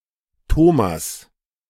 German: [ˈtoːmas]
De-Thomas.ogg.mp3